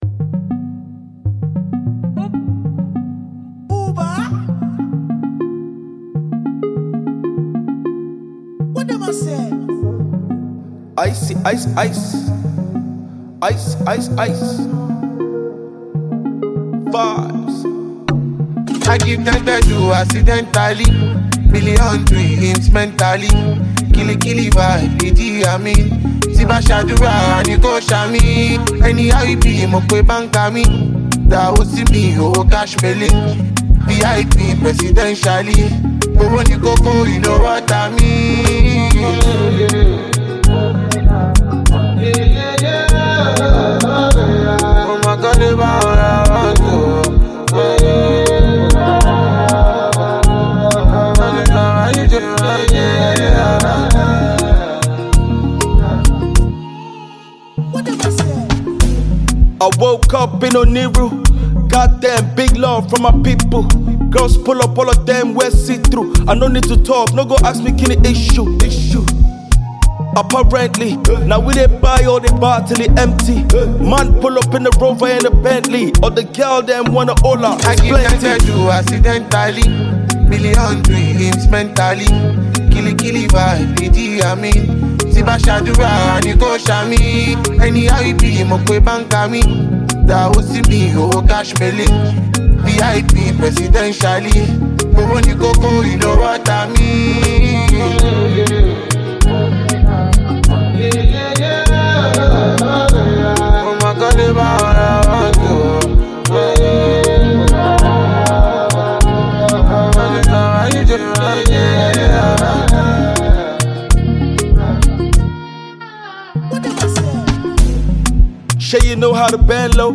Nigerian / African Music
African Music Genre: Afrobeats Released